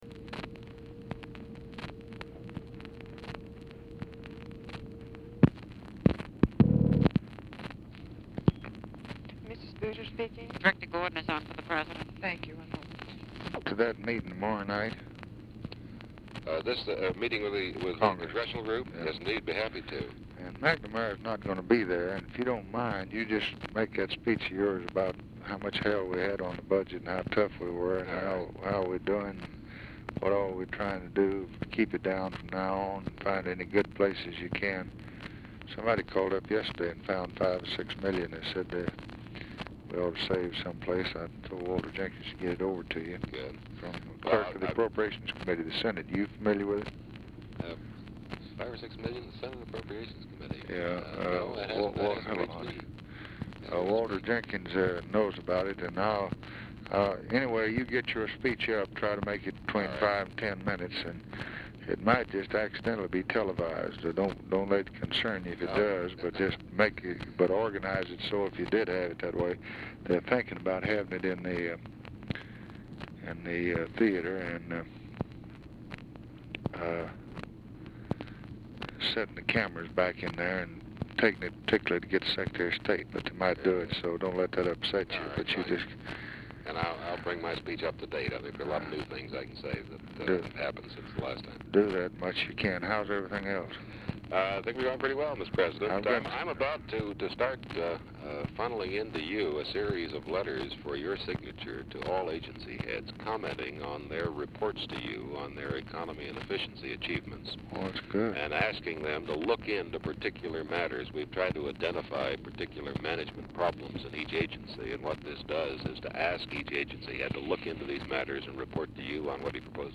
Telephone conversation # 2458, sound recording, LBJ and KERMIT GORDON, 3/10/1964, 6:19PM | Discover LBJ
Format Dictation belt
Location Of Speaker 1 Oval Office or unknown location